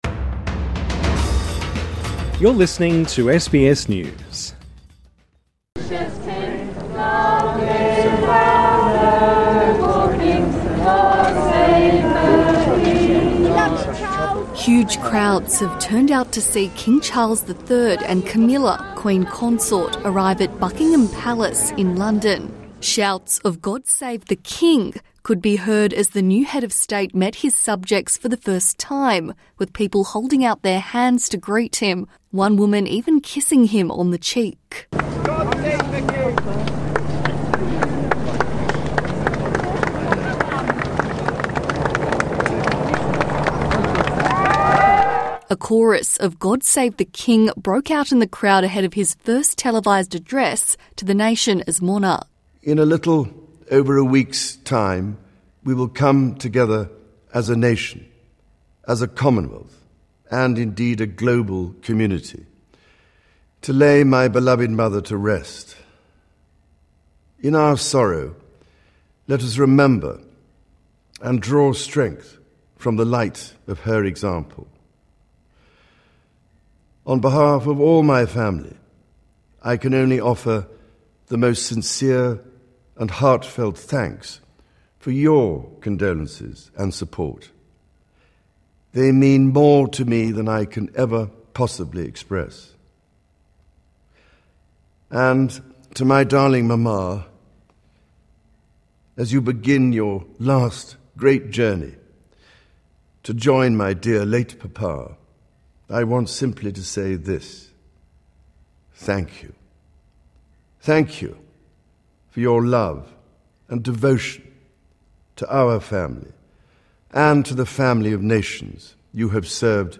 Shouts of, "God Save the King," could be heard as the new Head of State met his subjects for the first time, with people holding out their hands to greet him, one woman even kissing him on the cheek.